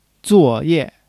zuo4--ye4.mp3